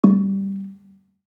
Gambang-G2-f.wav